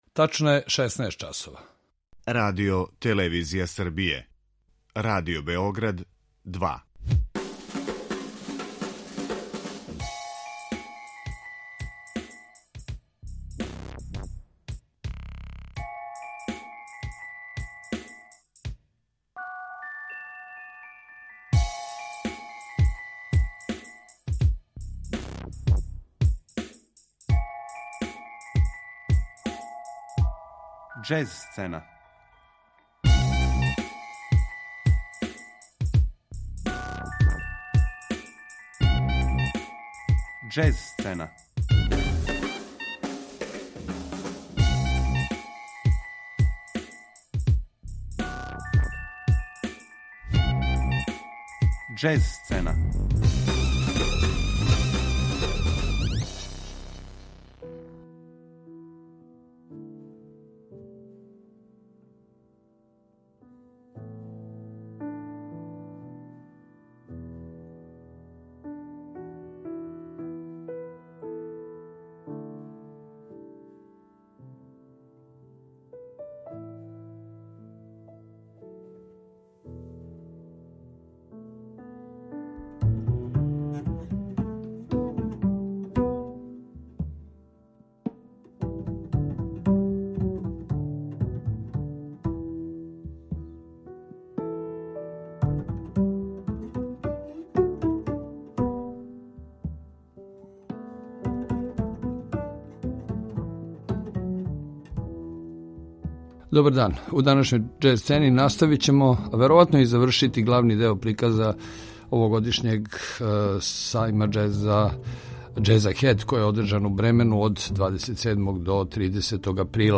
Причу илуструјемо снимцима извођача са ове манифестације.